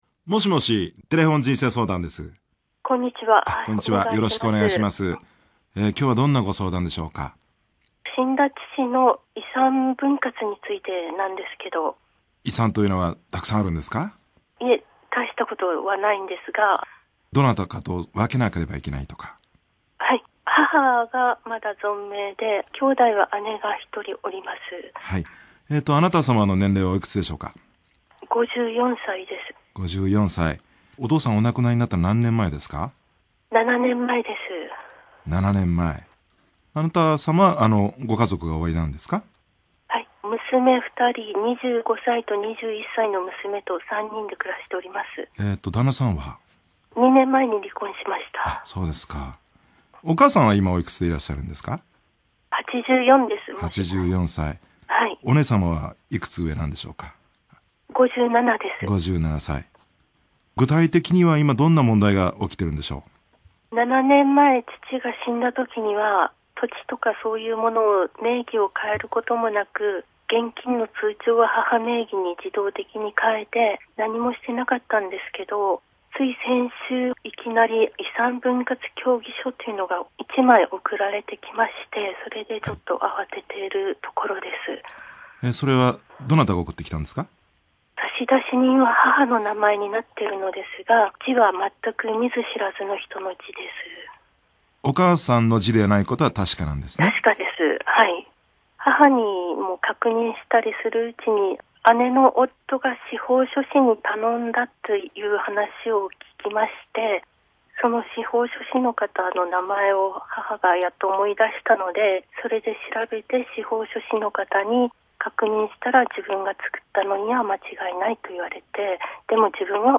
このときの「はい」は、音声レベルが上がったのかと思った。